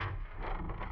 Everything was done inside Ableton. Kick was an 808 sample layered with another distorted 808 with some volume fades to blend them.
Quick hat pattern on top. The ride loop came from resampling a hat whilst twiddling the knobs on some Fx (can’t remember which).